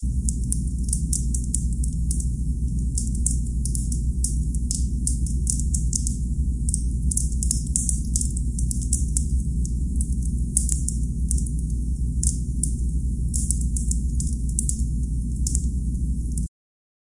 描述：这个是我在Freesound上找到的一些其他声音的混合，我用它来拍摄我制作的视频。
Tag: 噼啪声 火花 火焰 无人驾驶飞机 火花 裂纹 烧伤 能源 火焰 燃烧